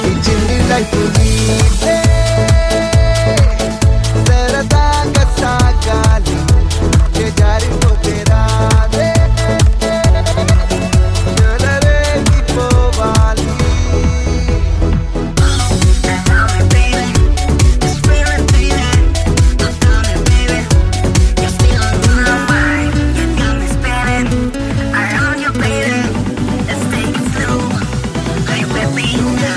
CategoryTelugu Ringtones